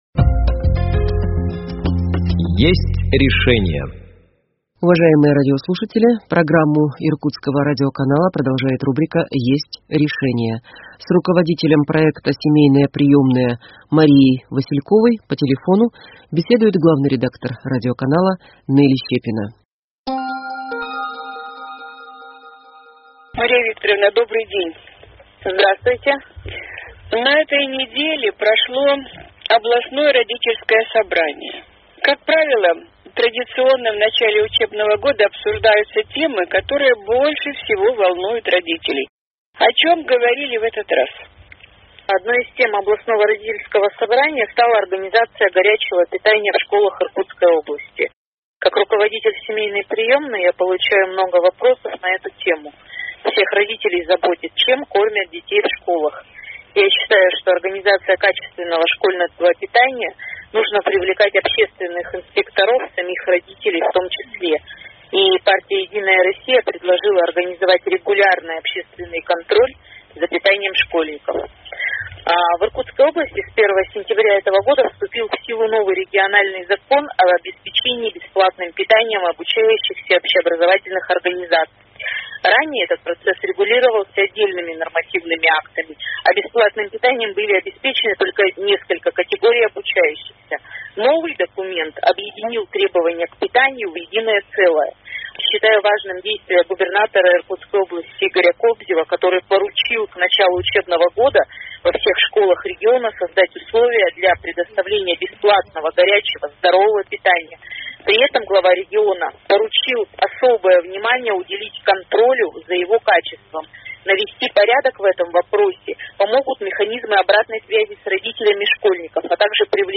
по телефону